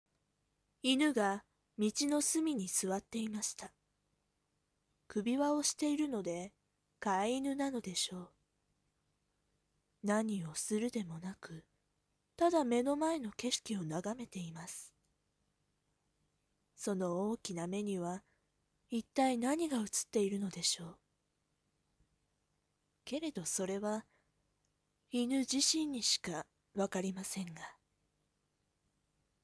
ナレーション〜道端の犬〜